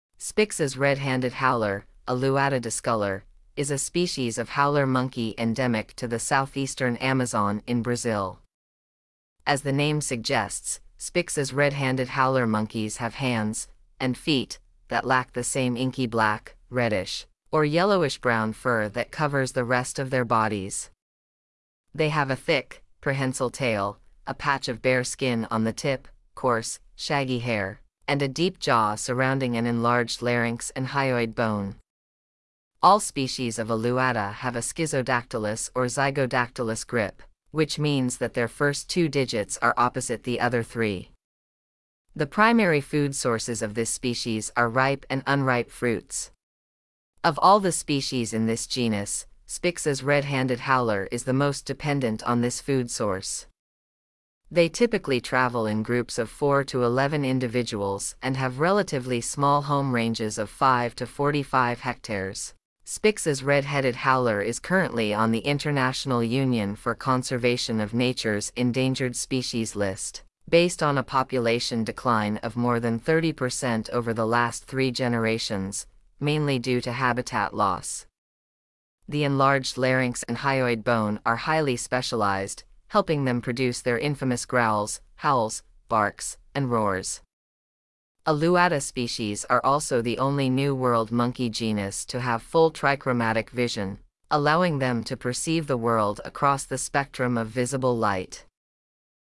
Spix's Red-handed Howler
• The enlarged larynx and hyoid bone are highly specialized, helping them produce their infamous growls, howls, barks, and roars.
Spixs-Red-handed-Howler.mp3